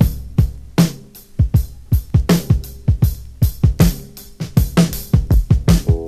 • 79 Bpm Drum Beat E Key.wav
Free drum groove - kick tuned to the E note. Loudest frequency: 786Hz
79-bpm-drum-beat-e-key-UPz.wav